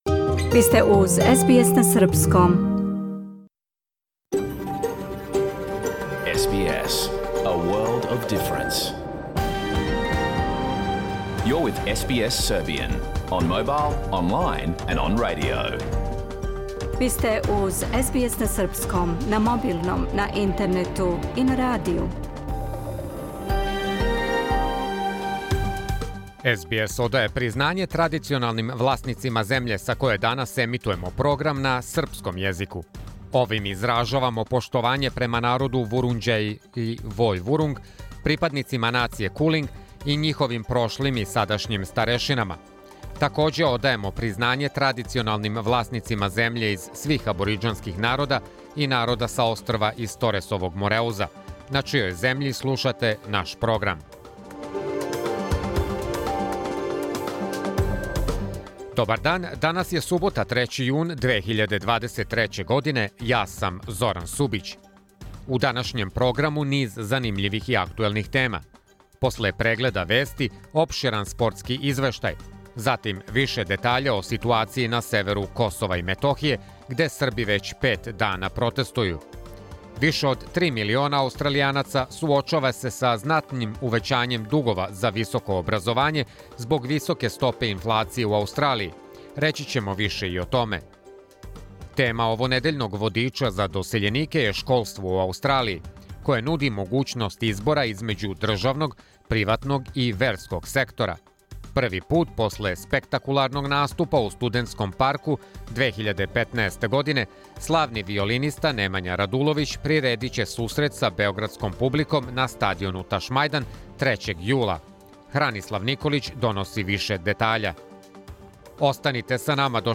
Програм емитован уживо 3. јуна 2023. године
Ако сте пропустили данашњу емисију, можете да је слушате у целини као подкаст, без реклама.